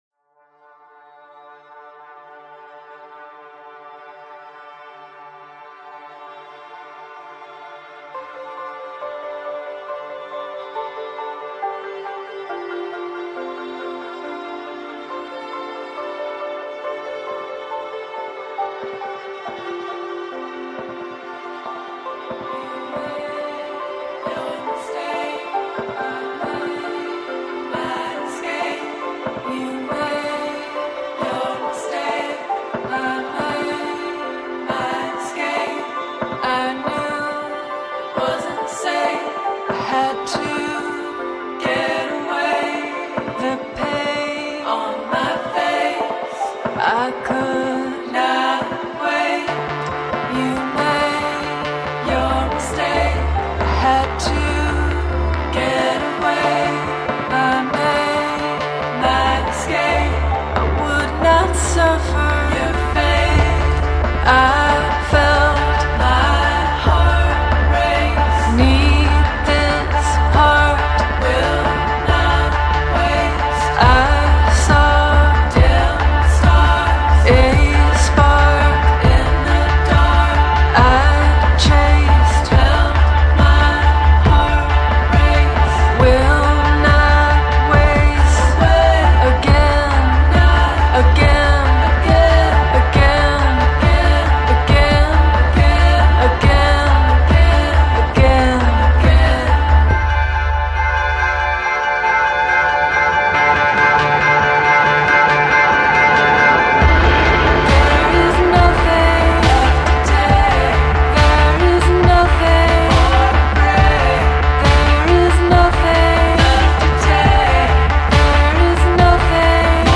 psychedelic indie pop
the lush and “medicinal” soundcape found in the second track